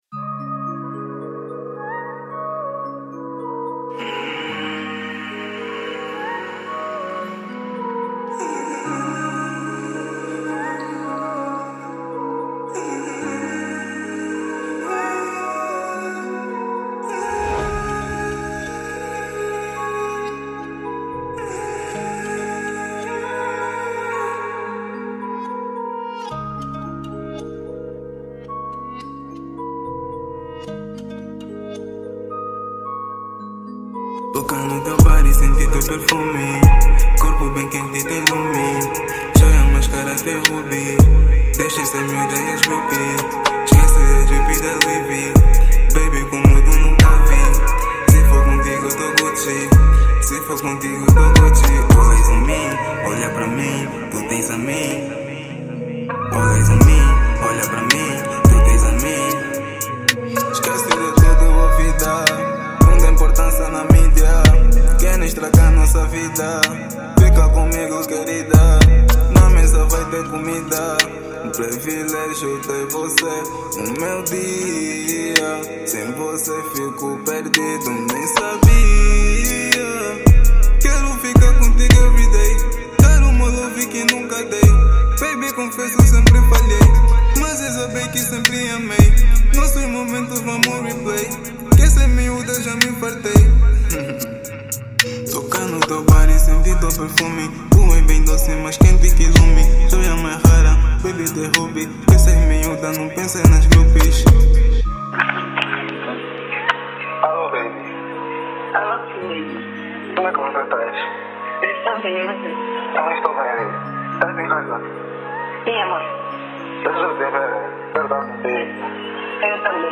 Género musical: Trap